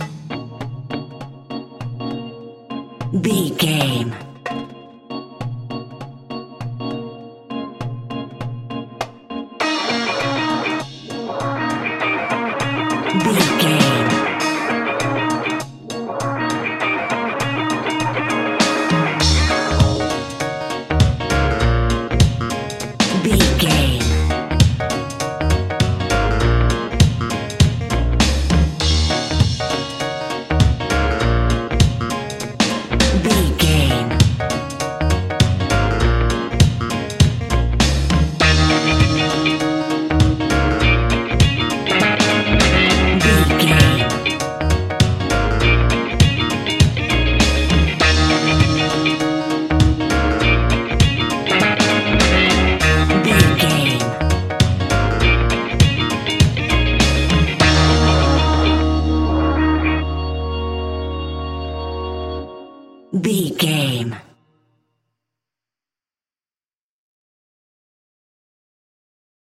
Aeolian/Minor
B♭
dub
laid back
chilled
off beat
drums
skank guitar
hammond organ
transistor guitar
percussion
horns